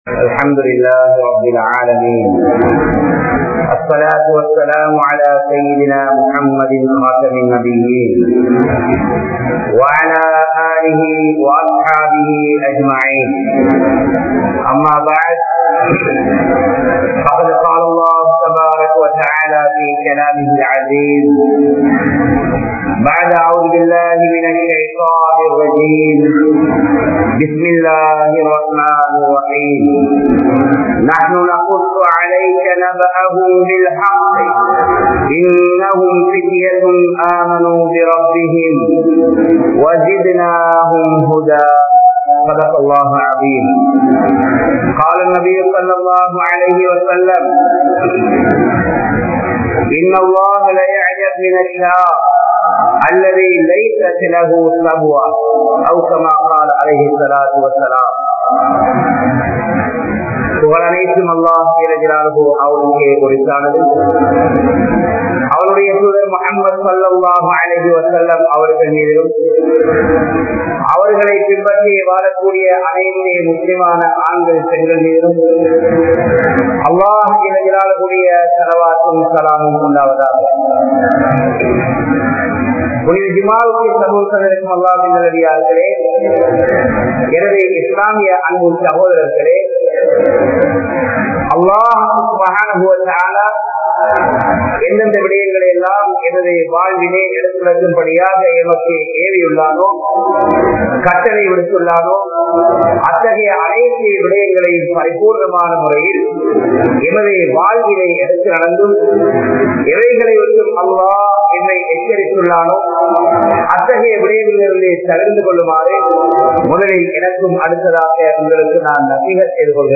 Islamiya Vaalifarhalin Mun Maathiri Yaar? (இஸ்லாமிய வாலிபர்களின் முன் மாதிரி யார்?) | Audio Bayans | All Ceylon Muslim Youth Community | Addalaichenai